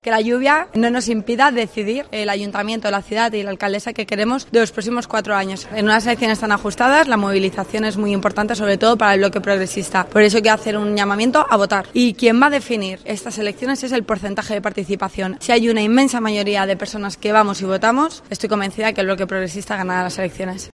Gómez ha señalado asimismo, en declaraciones a los periodistas tras depositar su voto en el Colegio Cavite, que afronta esta jornada con "mucha ilusión y mucha fuerza".